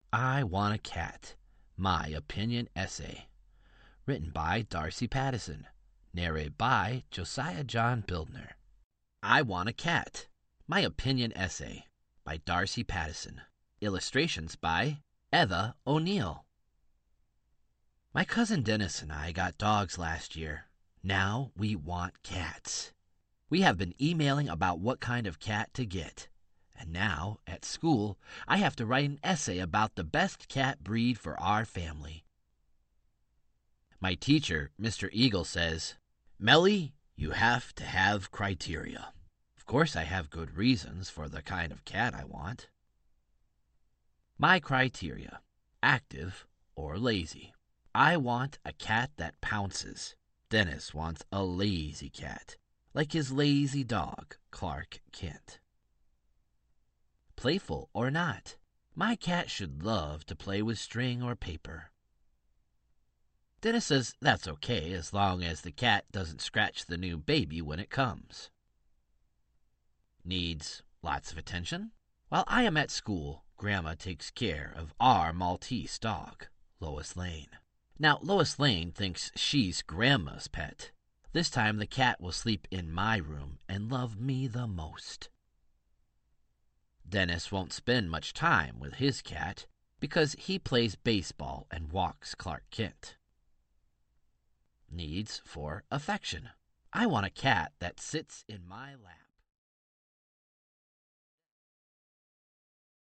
Audiobook - I Want a Cat